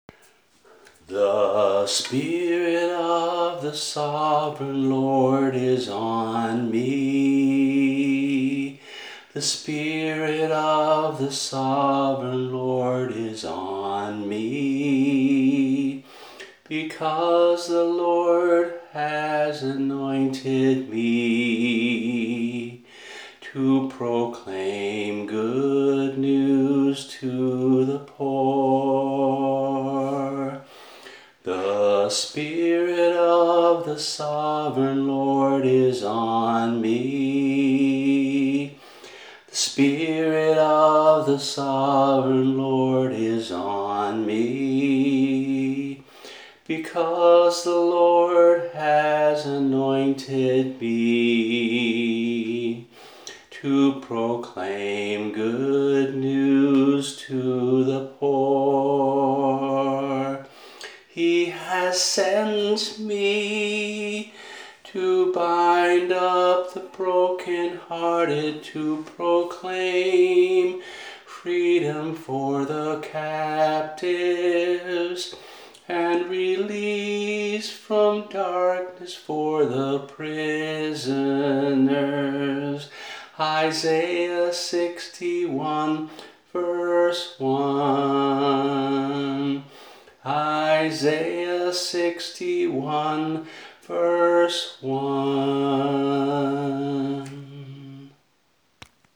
[MP3 - voice only]